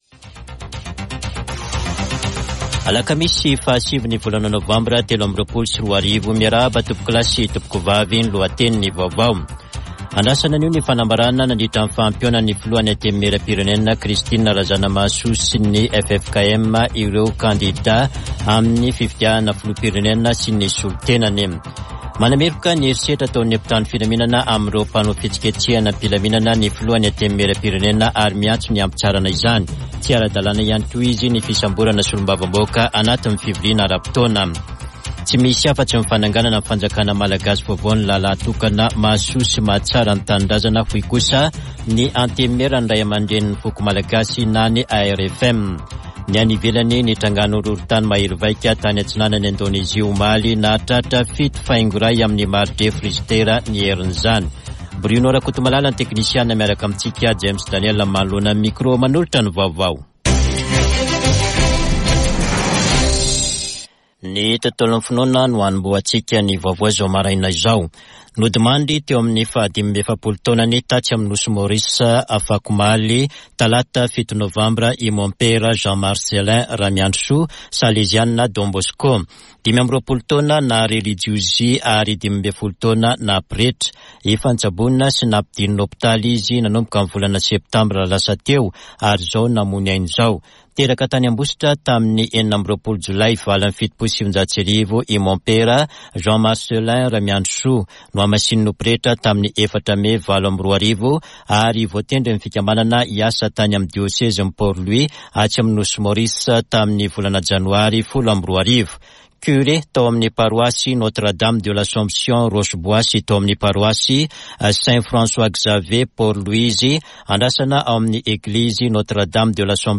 [Vaovao maraina] Alakamisy 9 nôvambra 2023